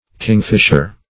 Kingfisher \King"fish`er\ (k[i^]ng"f[i^]sh`[~e]r), n. (Zool.)